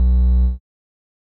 Techmino / media / sample / bass / 3.ogg